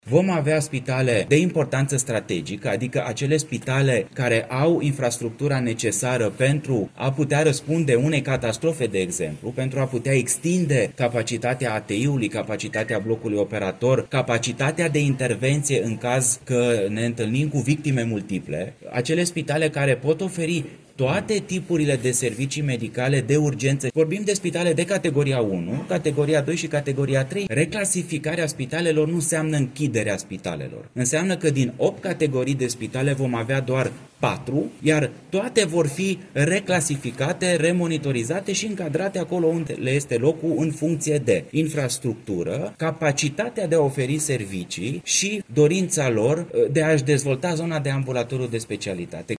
Ministrul Sănătății, Alexandru Rogobete, a anunțat la Timișoara că toate spitalele publice vor fi reclasificate, fără ca acest proces să implice închideri de unități.